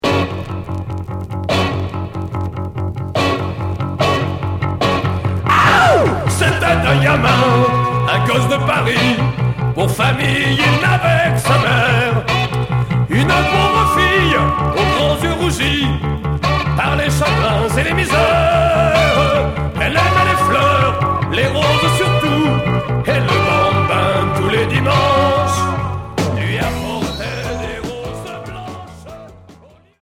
Rock variété